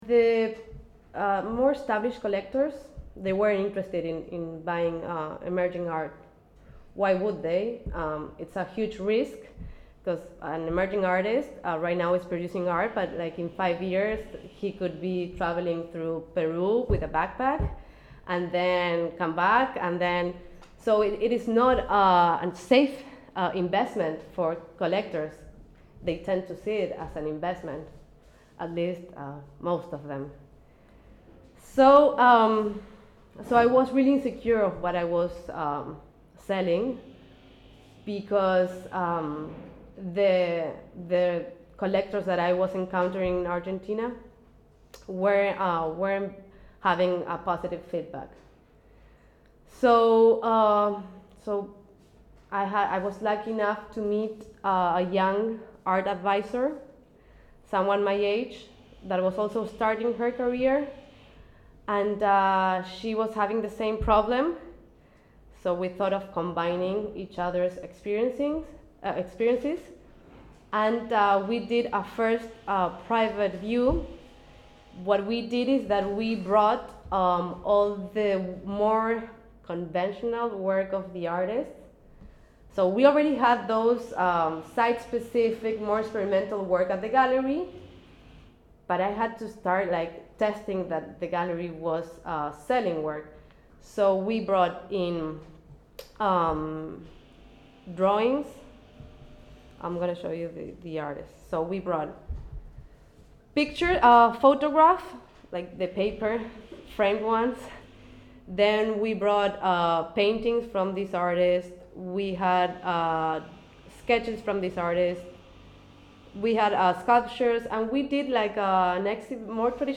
loeng käsitleb ühe kommertsgaleriina toimiva galerii tegevuse näitel seda, kuidas saab noori, alustavaid kunstnike esindav galerii olla jätkusuutlik.